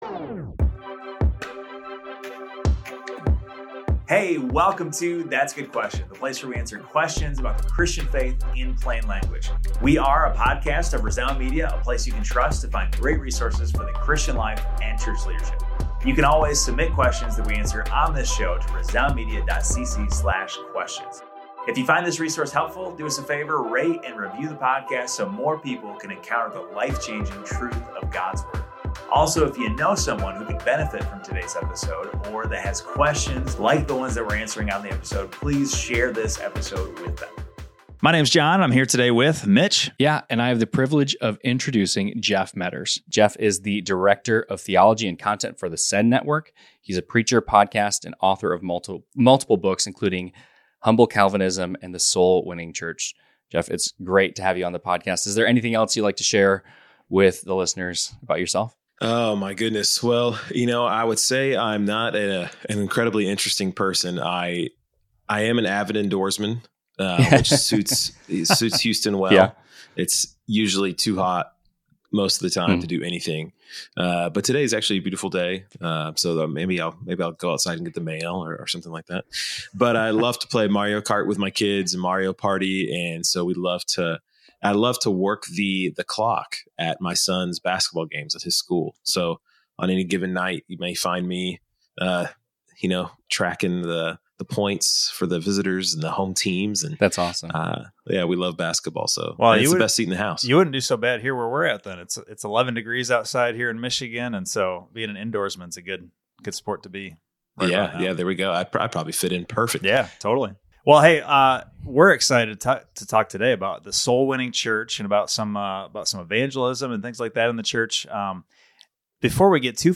Be the Hands and Feet: A Conversation